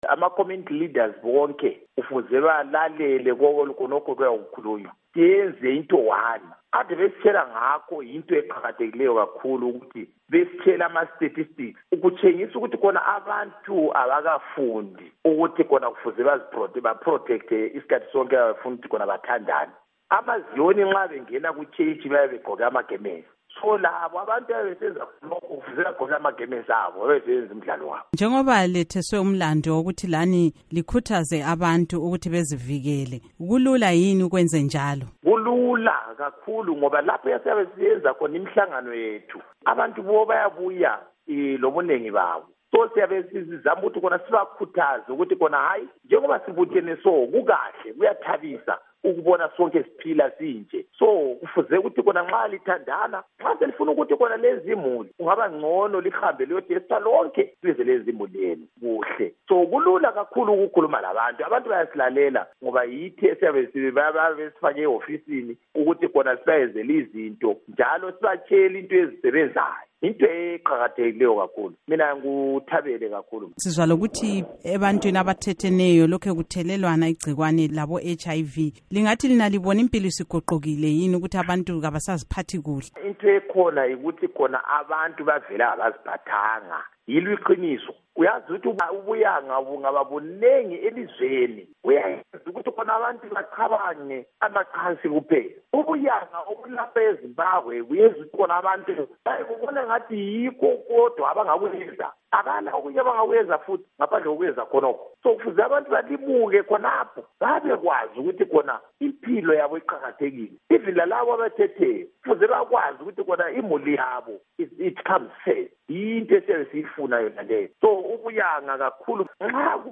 Ingxoxo LoKhansila Peter Moyo